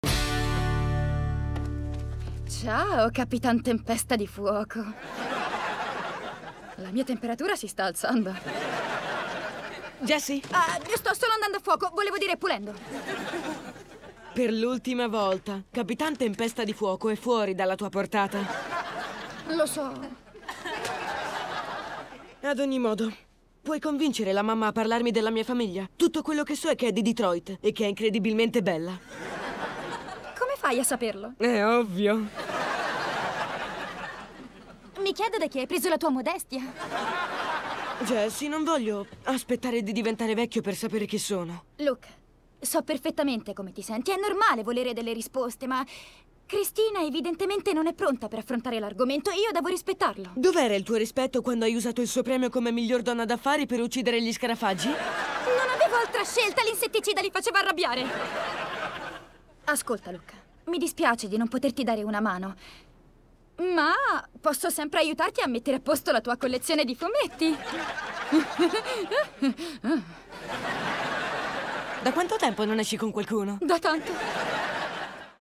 in cui doppia Cameron Boyce.